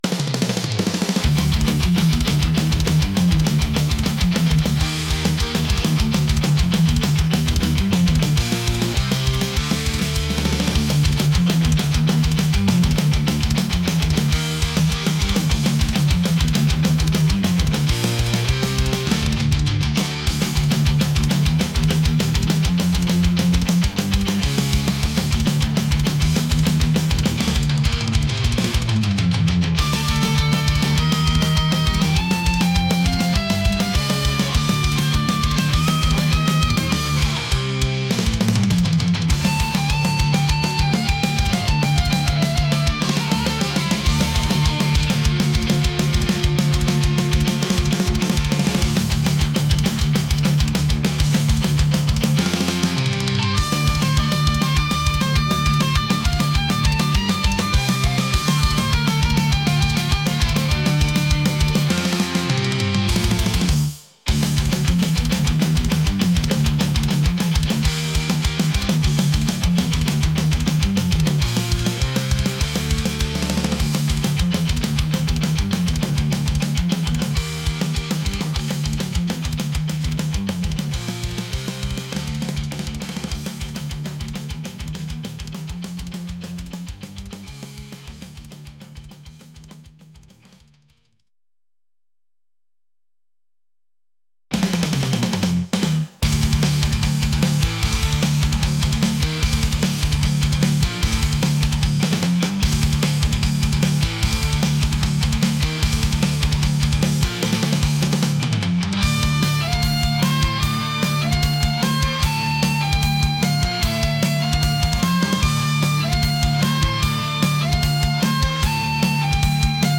aggressive | metal